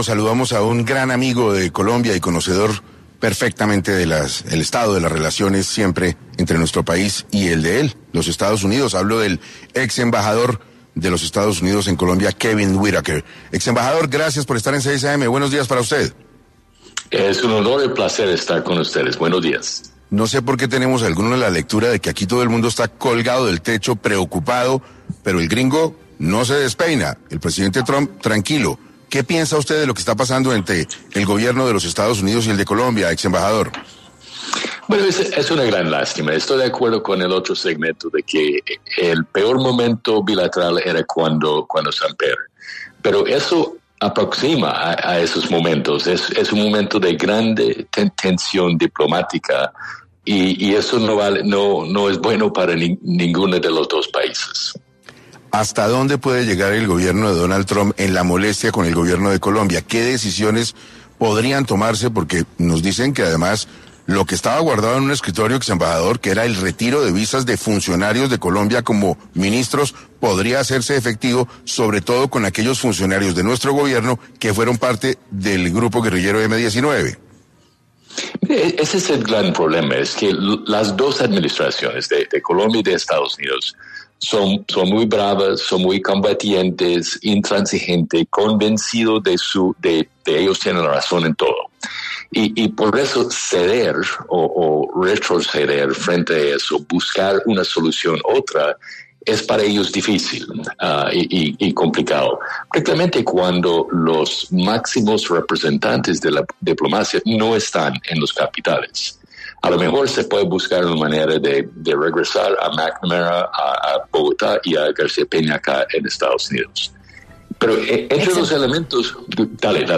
Kevin Whitaker, exembajador de EE.UU., estuvo en 6AM para abordar qué puede venir en la relación entre el gobierno de Donald Trump y el de Gustavo Petro.
En este orden de ideas, Kevin Whitaker, exembajador de EE.UU. en Colombia, pasó por los micrófonos de 6AM para sobre qué puede venir en la relación entre los dos gobiernos.